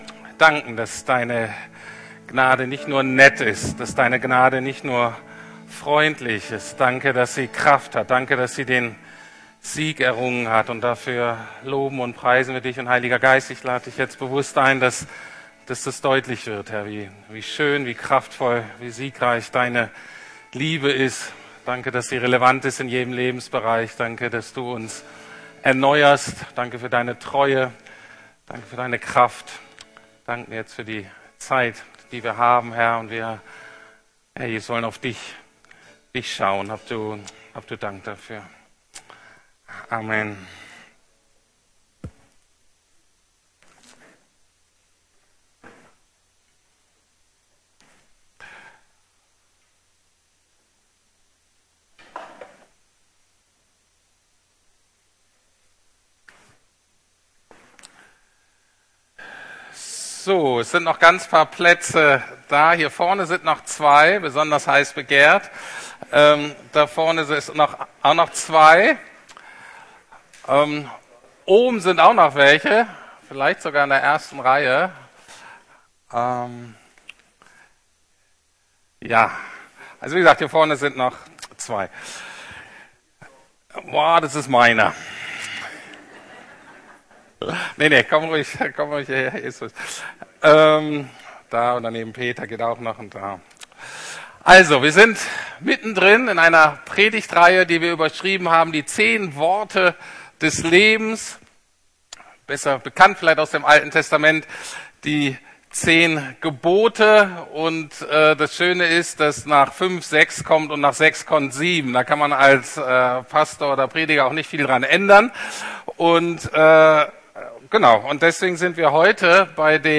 10 Worte des Lebens (Teil 7) ~ Predigten der LUKAS GEMEINDE Podcast